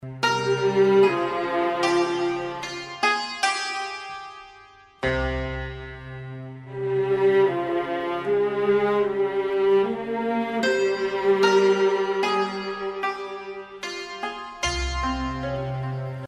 آهنگ موبایل بی کلام و سنتی